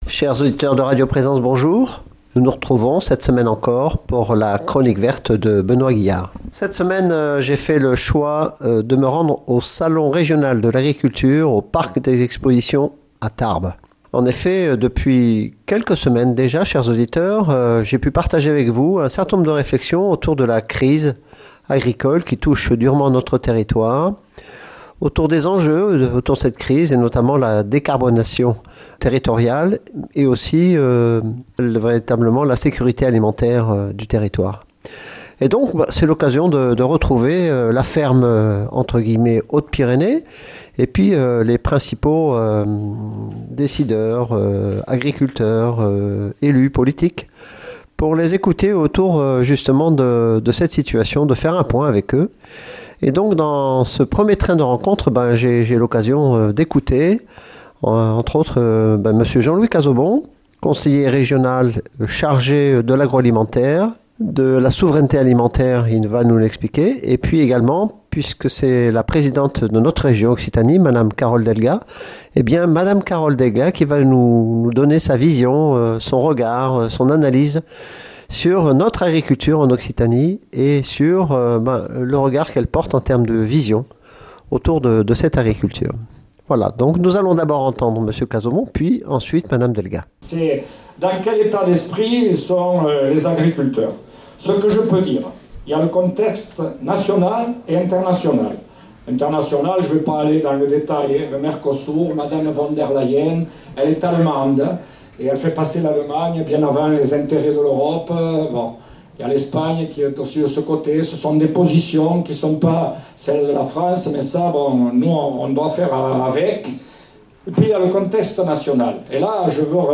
Cette chronique, dans le prolongement des précédentes, fait le point sur notre ferme "Hautes-Pyrénées", sur la colère et le malaise ressenti par les agriculteurs (notamment parce que le Mercosur est imposé par Mme von der Layen...) et ce, à l'occasion de l'inauguration de ce salon.
Nos auditeurs entendront tour à tour, le président de ce salon, Mr Jean-Louis CAZAUBON, également vice-président de la Région chargé de l'agro-alimentaire et de la souveraineté alimentaire, et Mme Carole DELGA, présidente de la Région, venue inaugurer ce salon. Ils s'expriment tous deux sur cette situation, nous partagent leurs regards en tant qu'élus régionaux et leurs volontés de soutenir cette agriculture occitane qui reste un fer de lance pour notre économie et pour nos emplois.